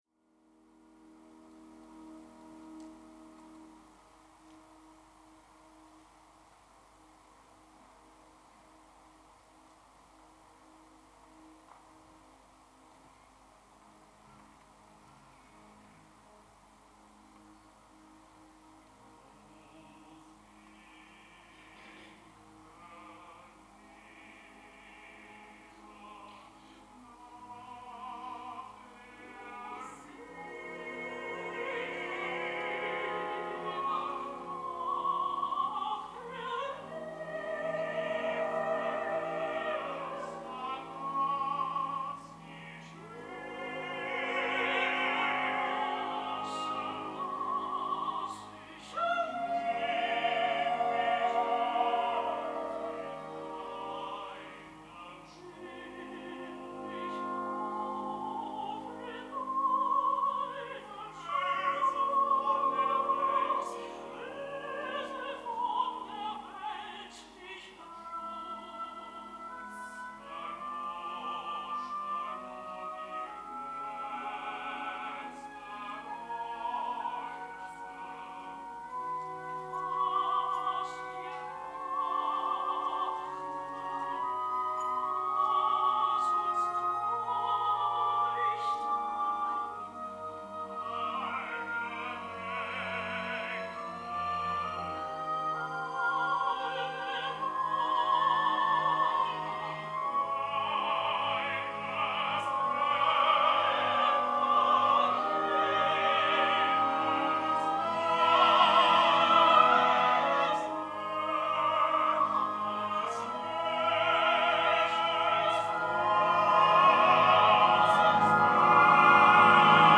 Dramatischer Sopran
Diese Hörproben sind Live-Mitschnitte durch Bühnenmikrophone, stellen also keine Studioqualität dar und sollen lediglich einen Stimm- und Interpretationseindruck vermitteln.